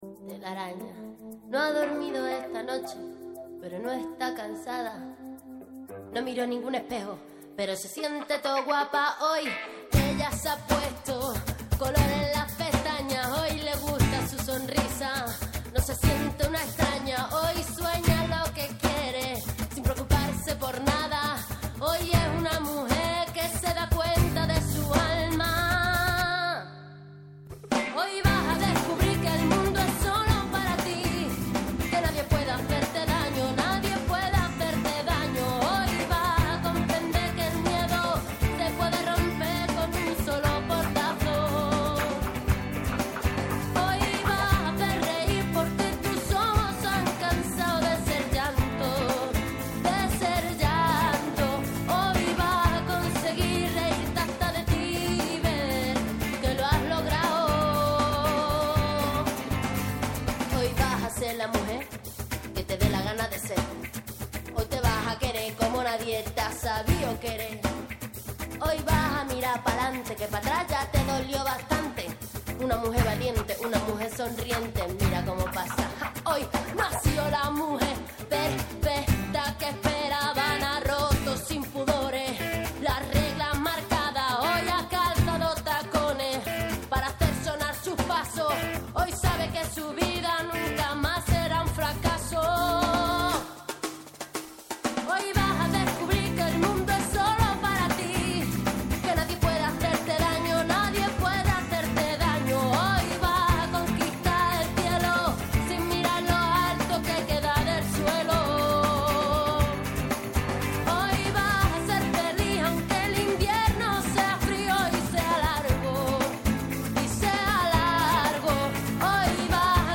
Emisión al aire... pero en el momento de la publicidad nos desenlazamos de la radio para añadir contenido exclusivo para esta versión Podcast.